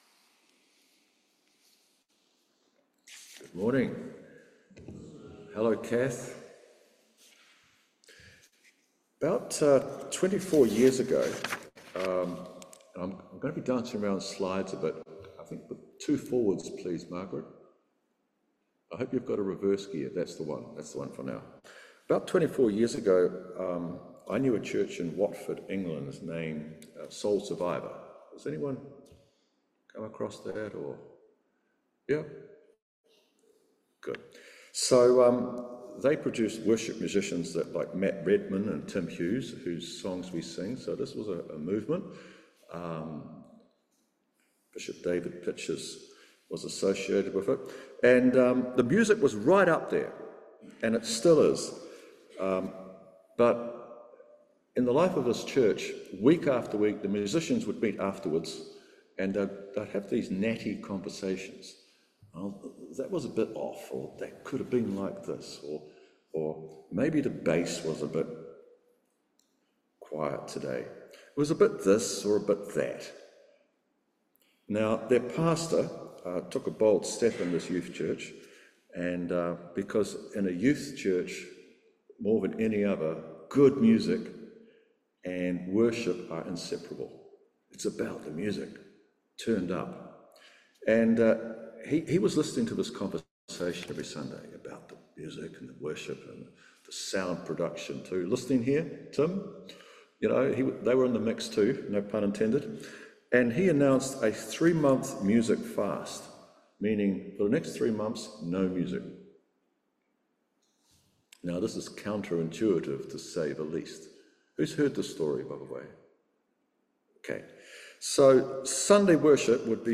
12b-19 Service Type: Morning Worship Do we worship God as he wants or as we want?